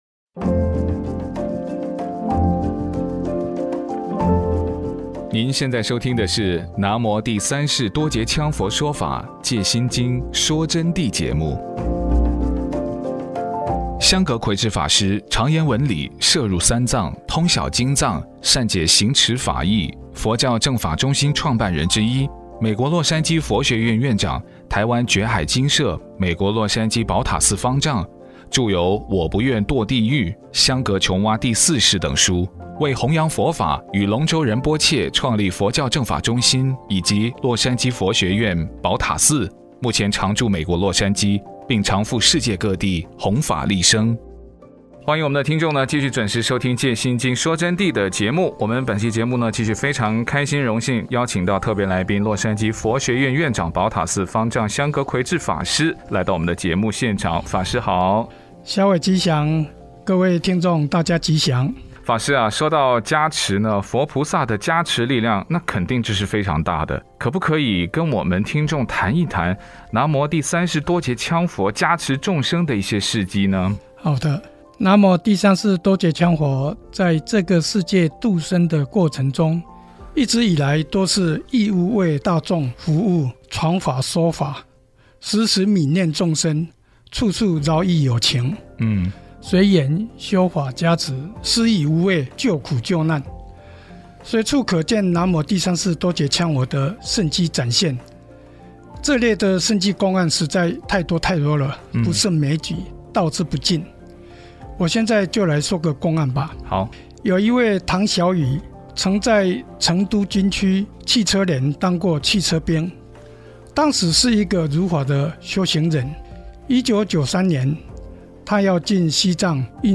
说到加持，佛菩萨的加持力量肯定是非常大的，让法师跟我们谈一谈南无第三世多杰羌佛加持众生的事迹。